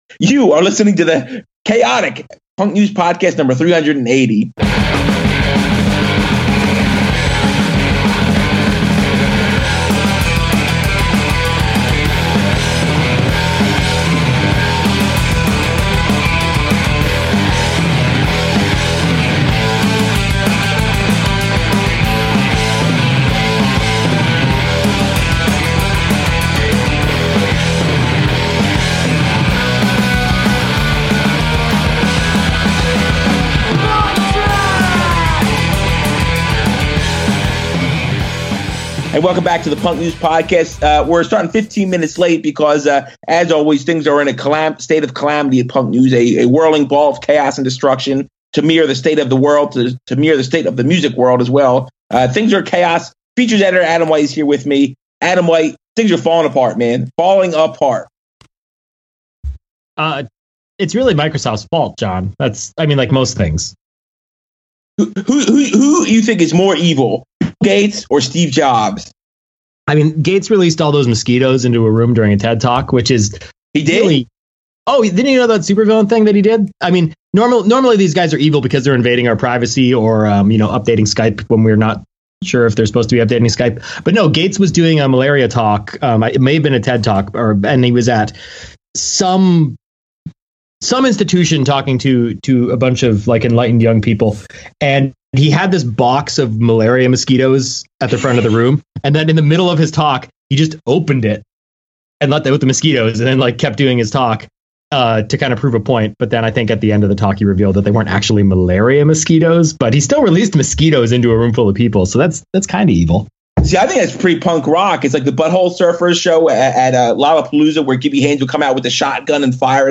The band Early Riser joins us for the show. Tegan and Sara's sing-along, Neil Young's discography website, the feds want Wu-Tang, Rollins leaves LA Weekly.